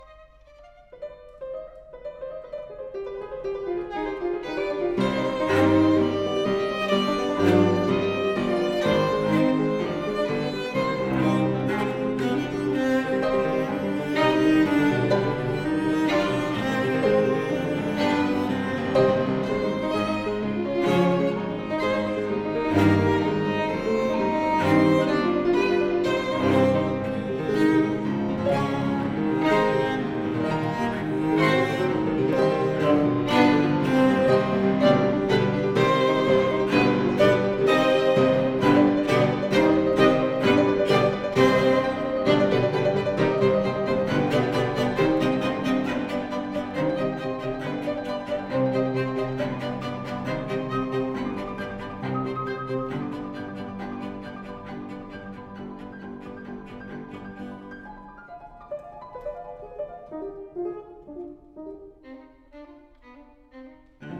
古典音樂、發燒音樂